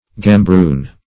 Gambroon \Gam*broon"\, n. A kind of twilled linen cloth for lining.